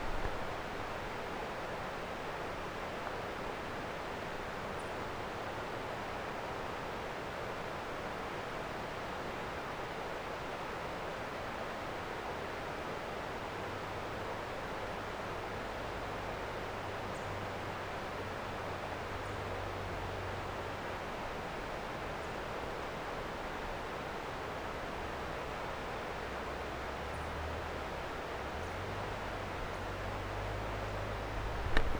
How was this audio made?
DenverRiver1.wav